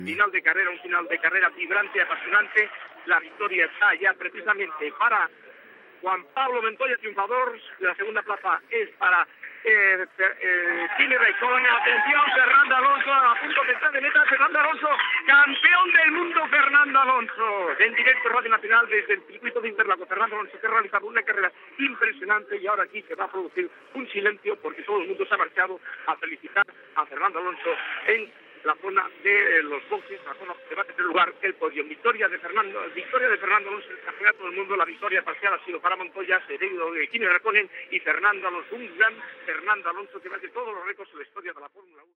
Narració del final del Gran Premi de Fórmula 1 al circuit d'Interlagos (Brasil), amb la victòria de Juan Pablo Montoya i la proclamació de campió del món de pilots de Fórmula 1 de Fernando Alonso.
Esportiu